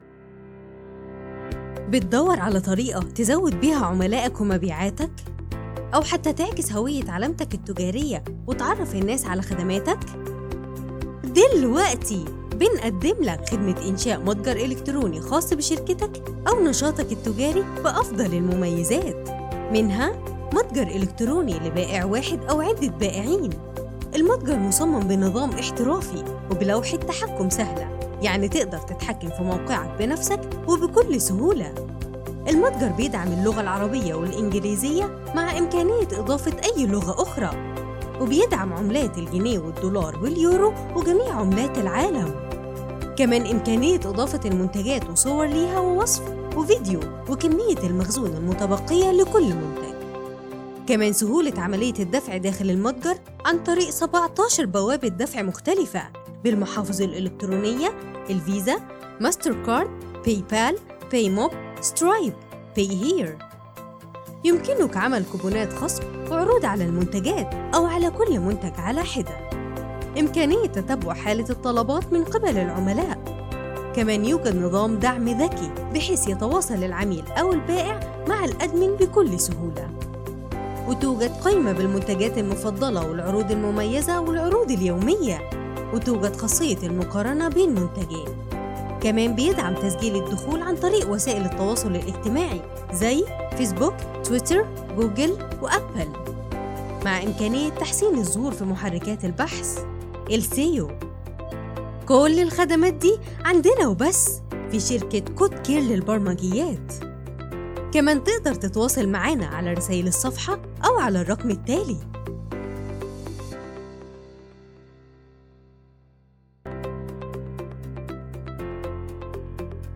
FeMale Voices